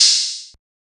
TM88 - OPEN HAT (6).wav